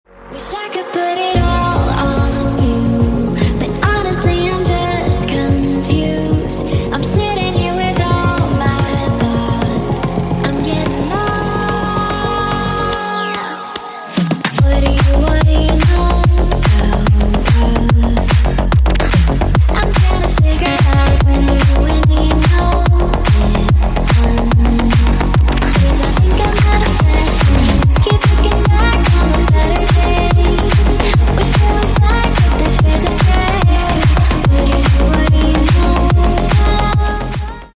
Клубные Рингтоны » # Громкие Рингтоны С Басами
Рингтоны Электроника